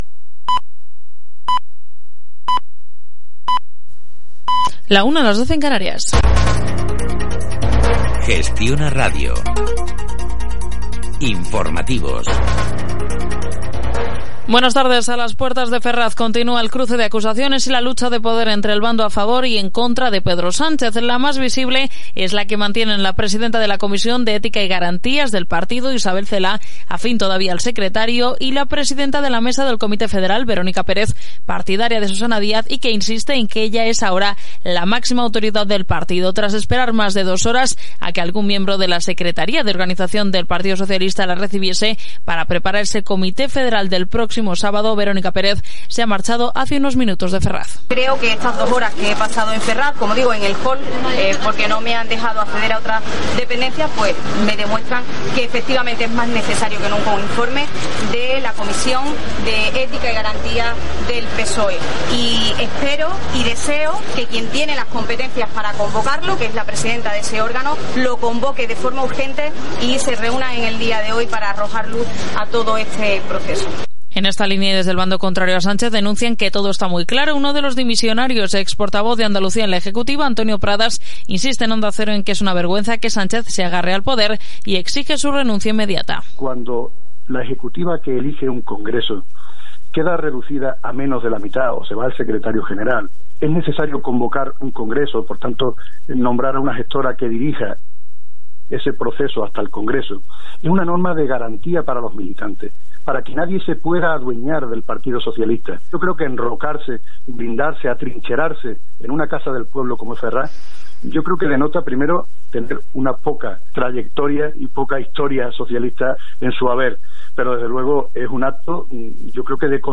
El programa de radio ‘Enfermedades Raras’ del 29 de septiembre de 2016